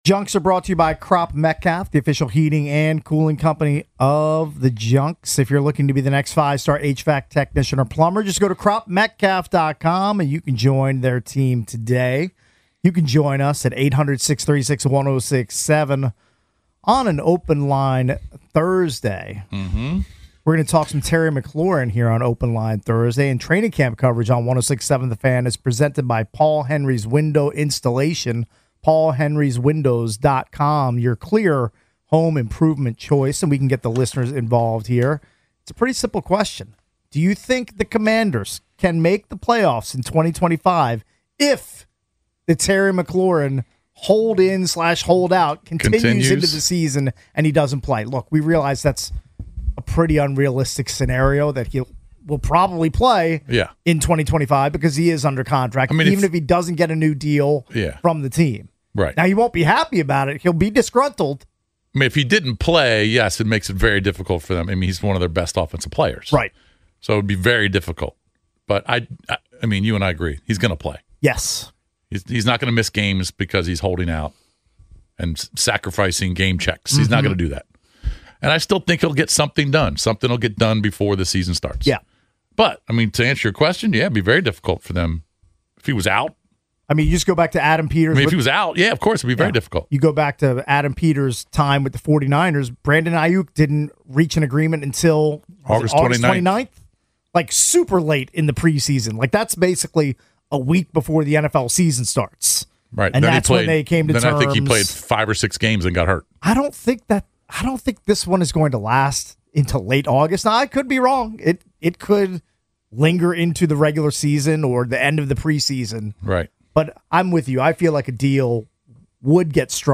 The Sports Junkies Audacy Sports, Comedy 4.6 • 1.6K Ratings 🗓 31 July 2025 ⏱ 14 minutes 🔗 Recording | iTunes | RSS 🧾 Download transcript Summary From 07/31 Hour 3: The Sports Junkies debate if the Commanders can win without Terry McLaurin.